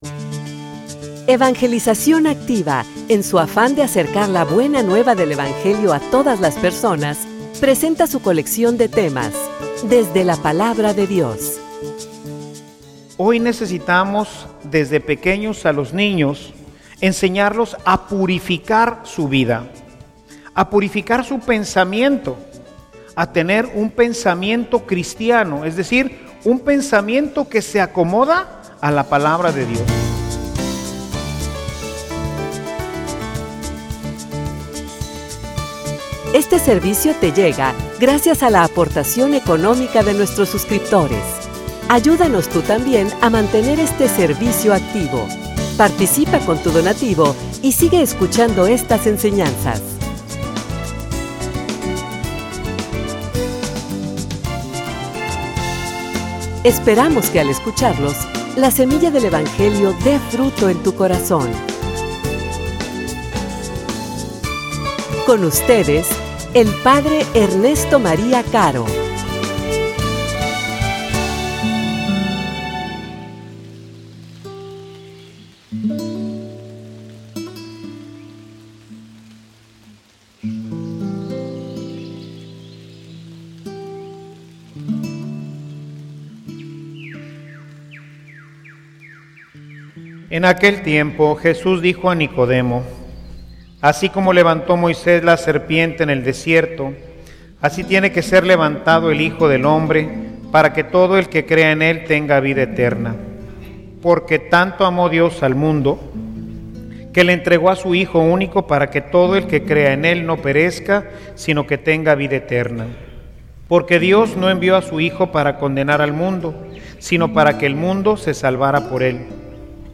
homilia_La_Palabra_y_los_Profetas.mp3